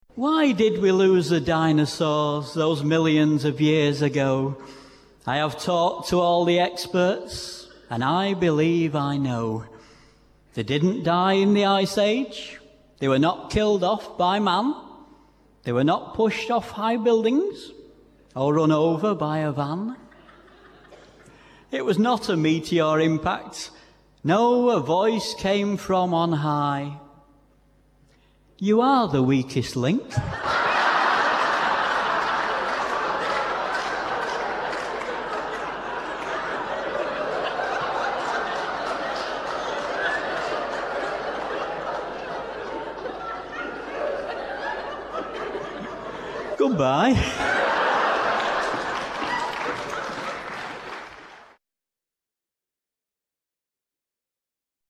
AROVERTHERAPY  (CD-$14.95)--comedy poetry
All these hilarious cuts were recorded live at various festivals around the UK.